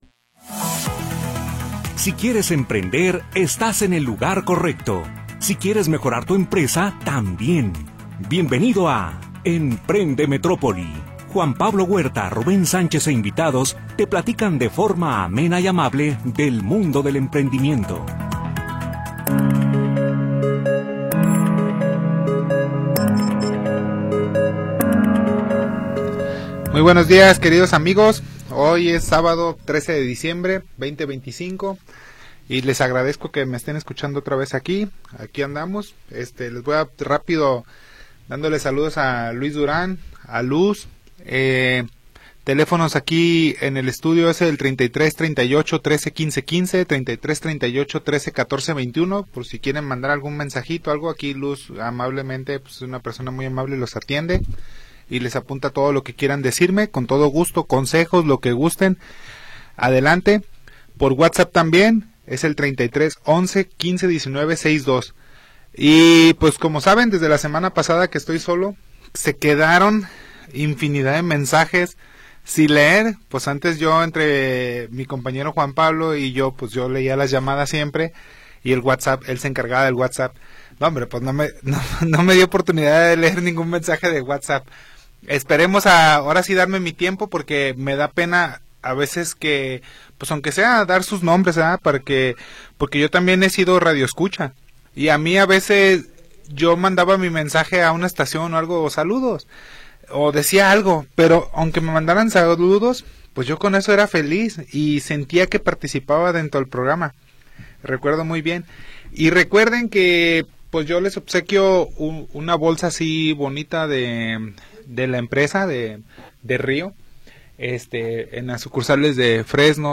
Programa transmitido el 13 de Diciembre de 2025.